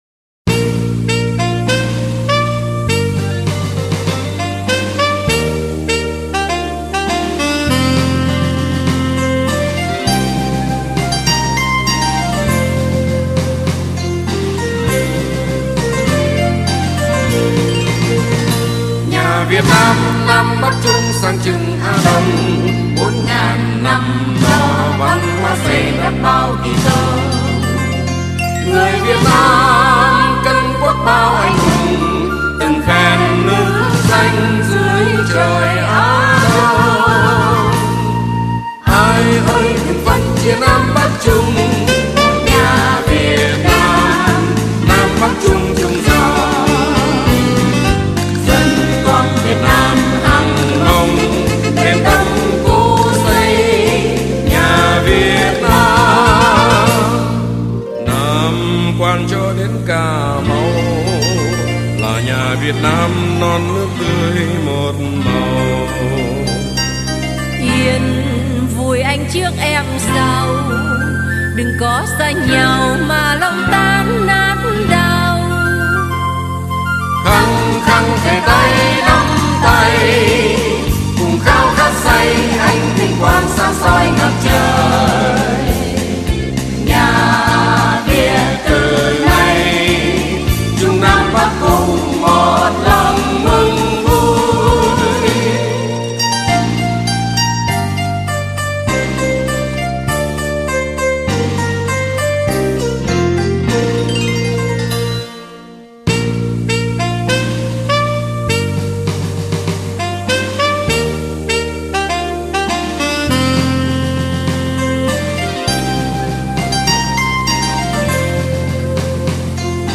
Hợp ca